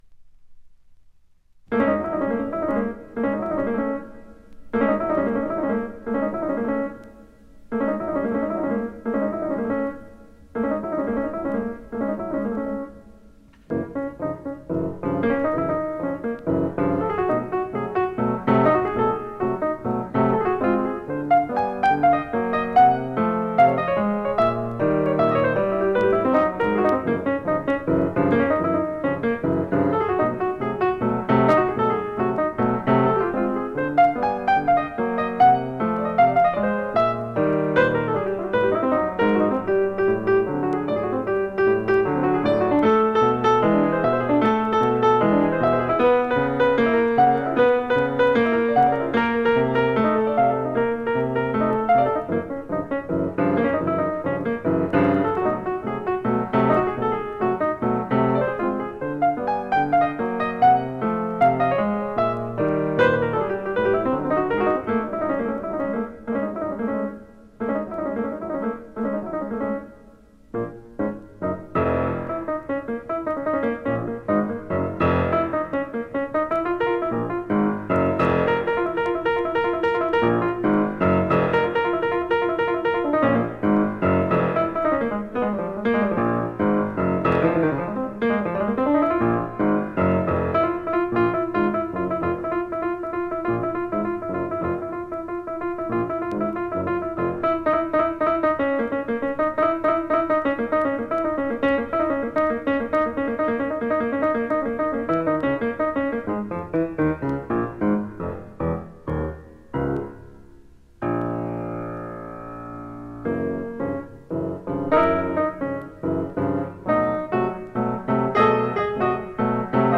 piano solo
The restless, edgy feeling